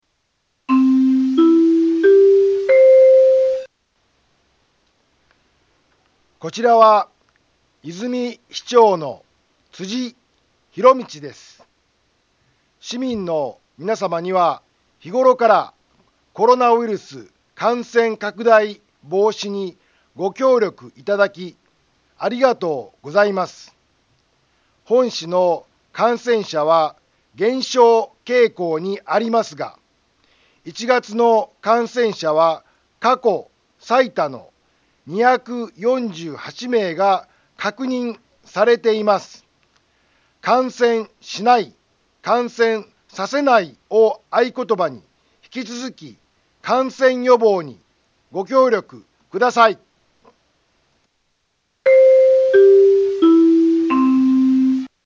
Back Home 災害情報 音声放送 再生 災害情報 カテゴリ：通常放送 住所：大阪府和泉市府中町２丁目７−５ インフォメーション：こちらは、和泉市長の辻 ひろみちです。